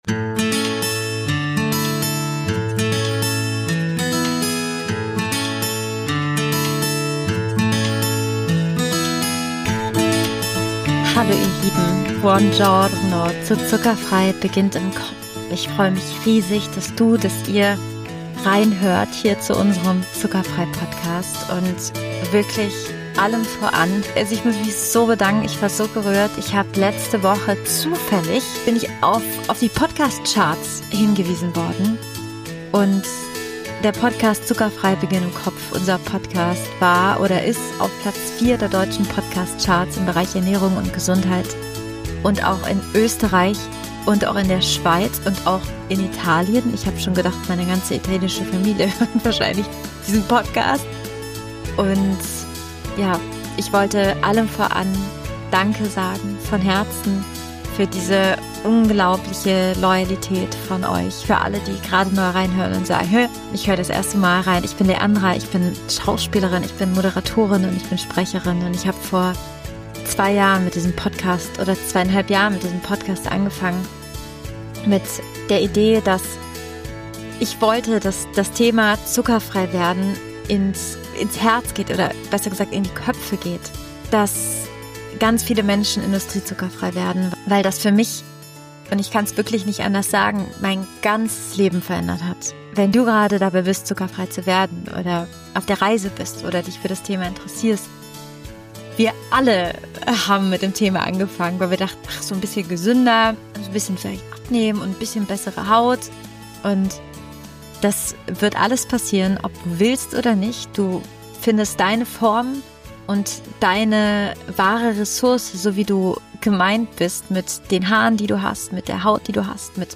Experteninterviews, Solofolgen mit wichtigsten Learnings und ganz vielen Aha-Erlebnissen.